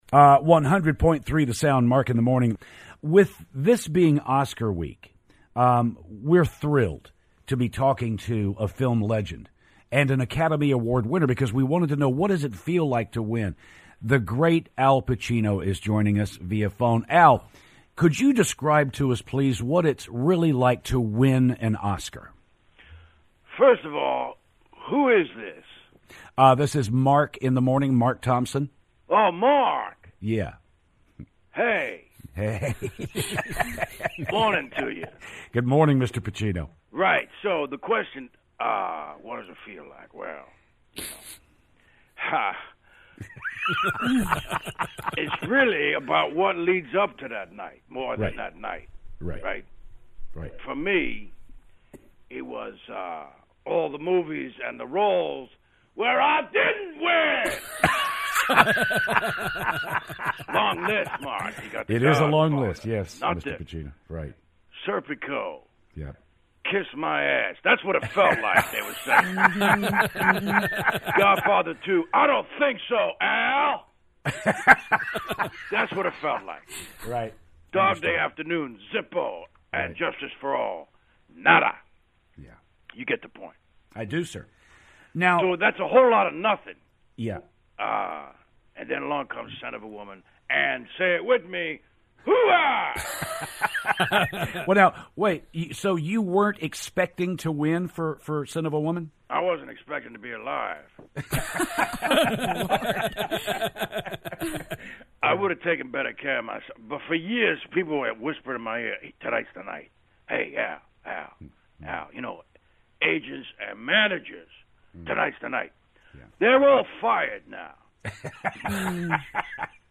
Oscar winner Al Pacino calls the show and explains the feeling of winning an Oscar.